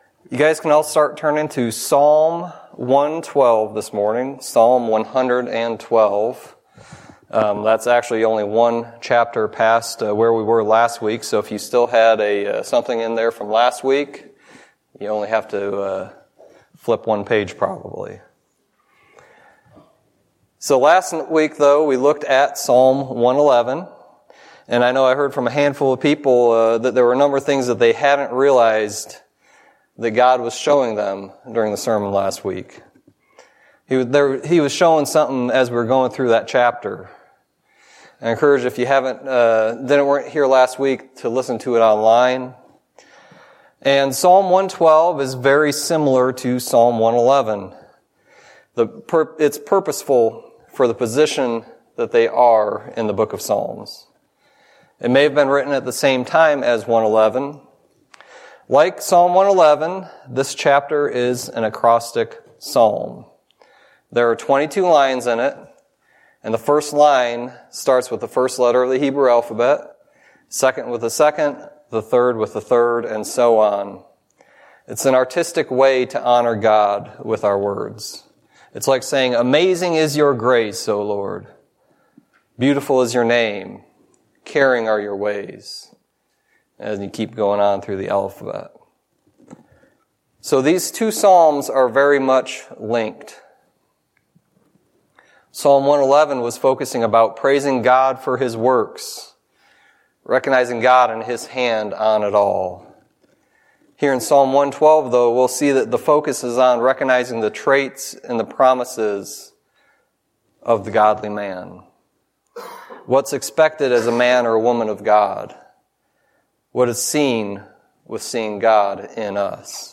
Sermon messages available online.
Psalm 112:1-10 Service Type: Sunday Teaching God showed us Himself through Psalm 111